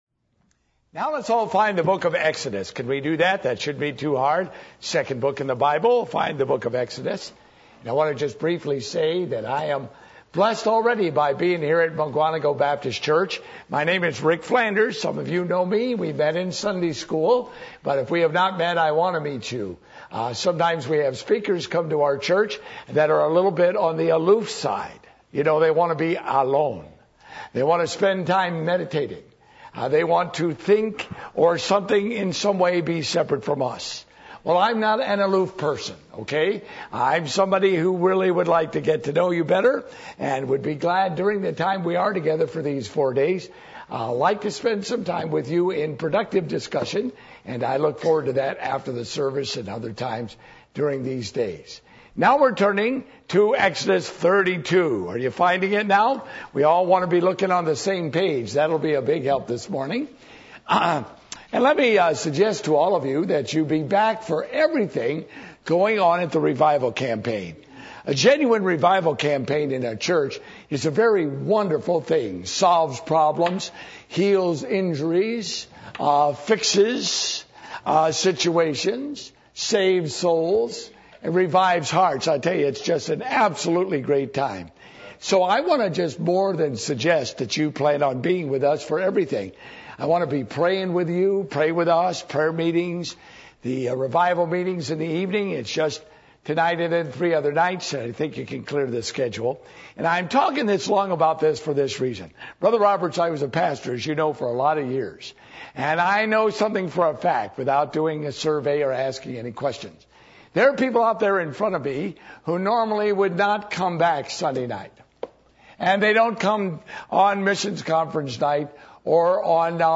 Exodus 32:1-10 Service Type: Revival Meetings %todo_render% « Why Pray For Revival?